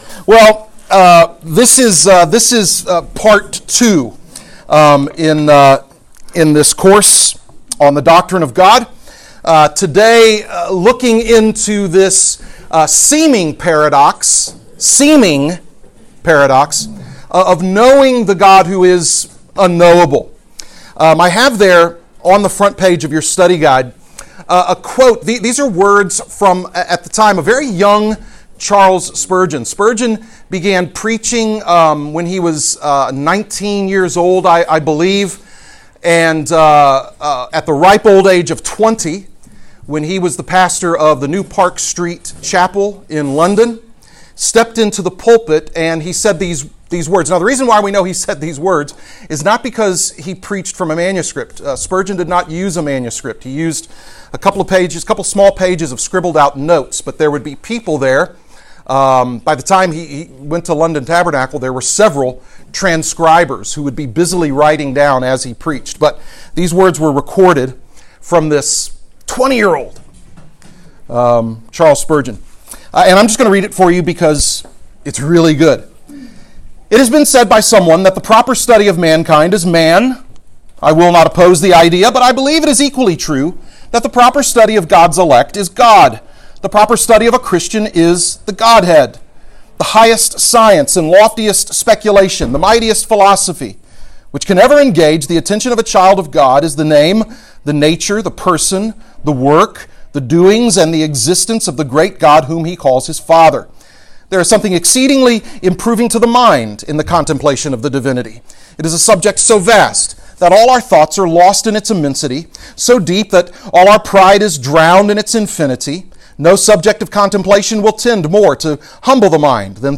Portions of the audio have been edited during times of class interaction due to low sound quality.